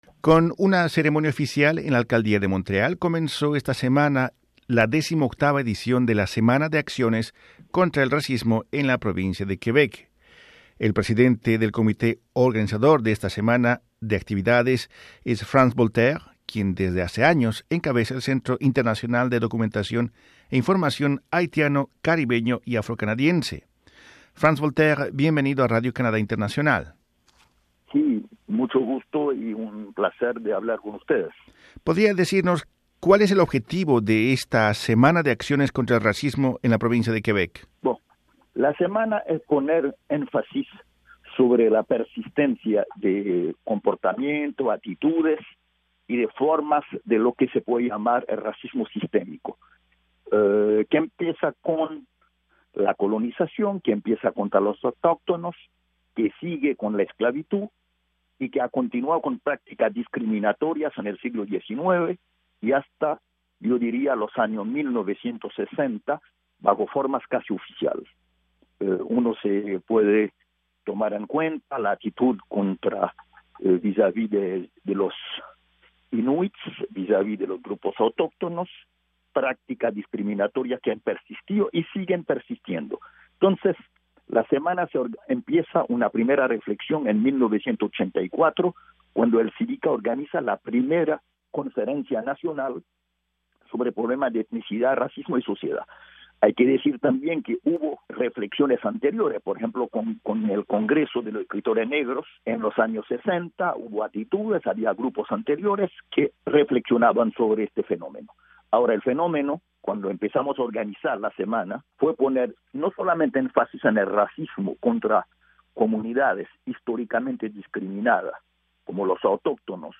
Entrevistado por Radio Canadá Internacional, él explica que esta semana de acciones contra el racismo tiene el objetivo de “hacer énfasis sobre la persistencia del racismo sistémico”, explorar los discursos que la alimentan, y proponer iniciativas que requieren de una clara voluntad política de arte de los poderes del Estado para contener el regreso de mensajes racistas que se creía habían acabado tras la derrota del fascismo y el nazismo a mediados del siglo XX.